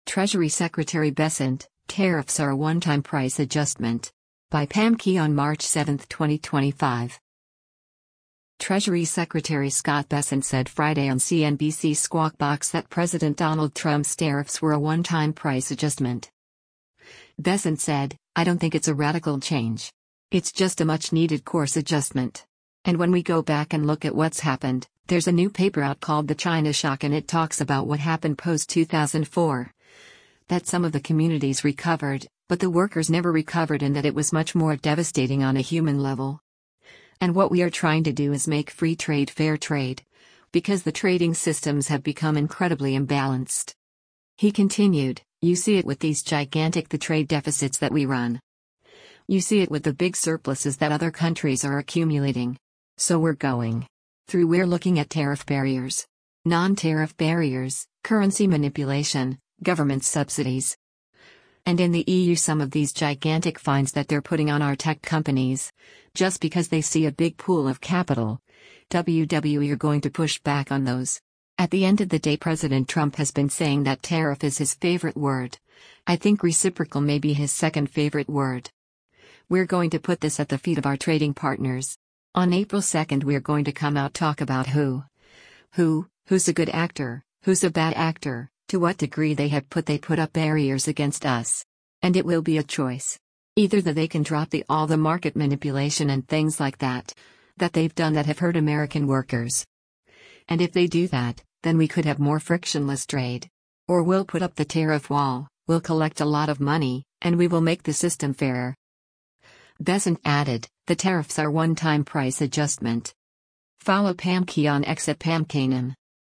Treasury Secretary Scott Bessent said Friday on CNBC’s “Squawk Box” that President Donald Trump’s tariffs were a “one-time price adjustment.”